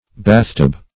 bathtub \bath"tub\ n.